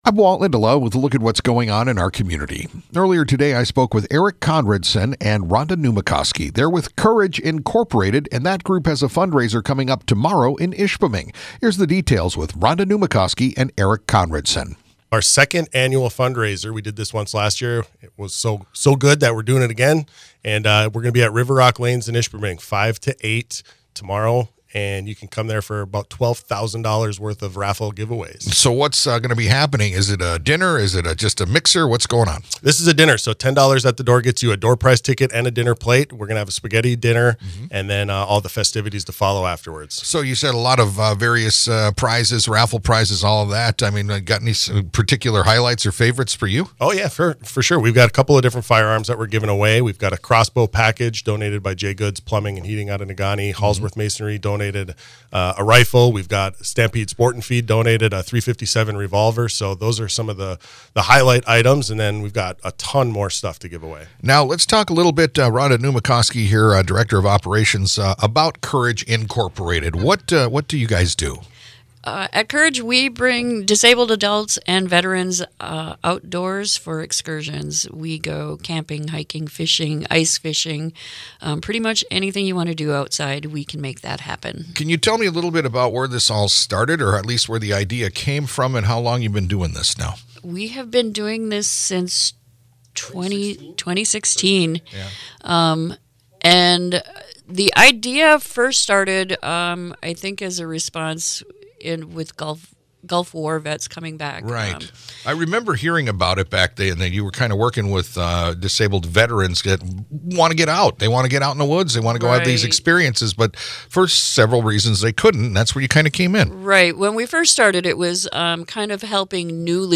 talked about the group and the fundraiser today